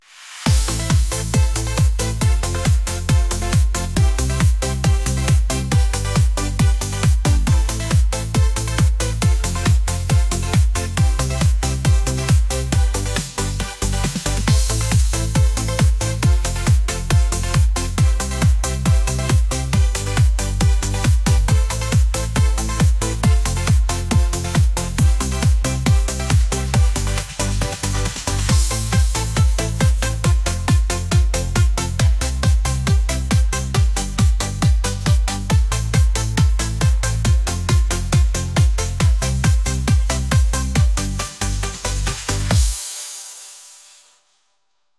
energetic | pop